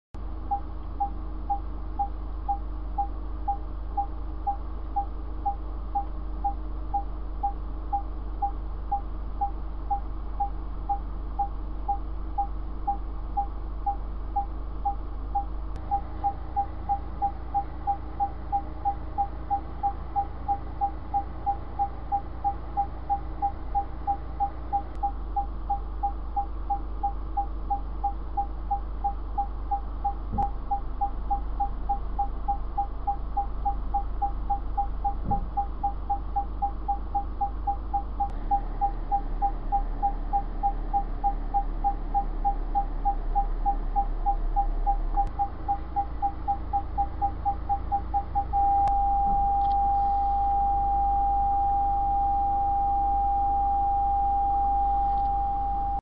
На этой странице собраны звуки парктроника — сигналы, которые издает автомобиль при парковке или обнаружении препятствий.
4. Разные варианты с пищащим парктроником n4. Несколько модификаций с пищащим парктроником n4. Варианты оснащения с пищащим парктроником